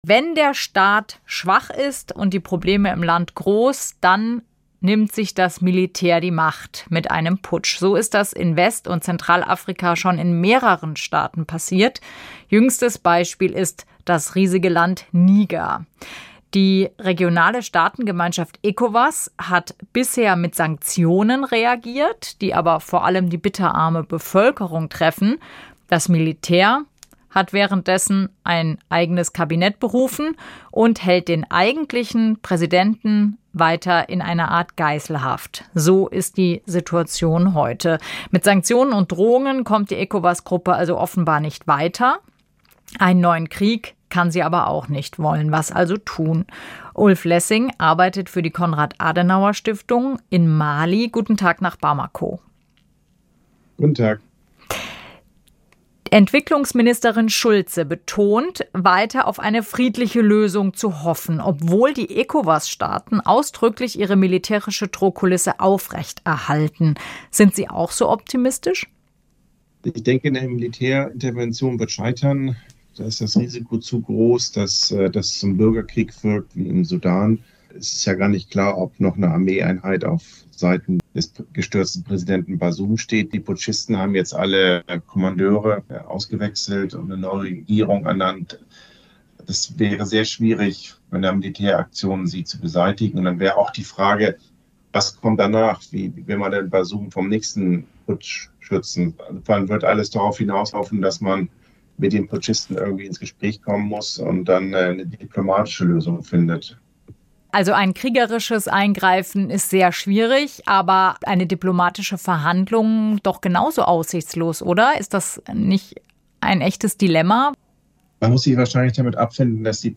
experte-zur-krise-in-niger-militaerintervention-wird-scheitern.m.mp3